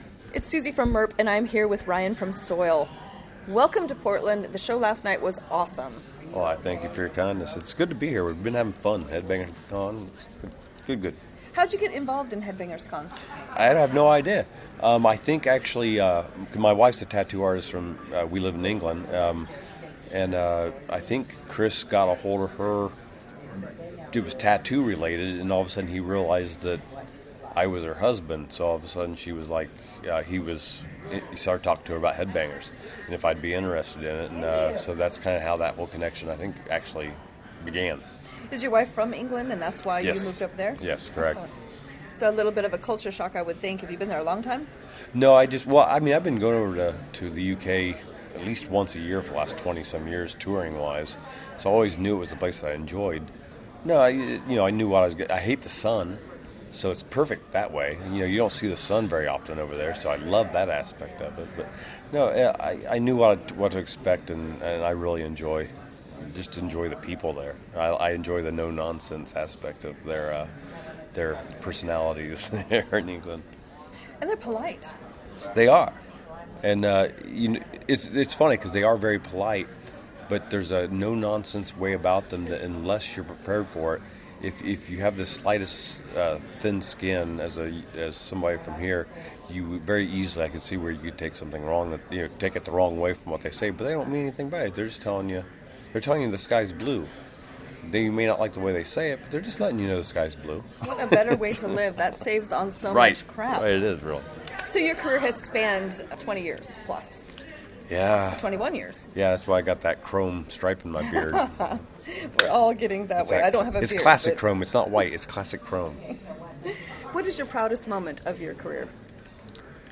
Interviews - Audio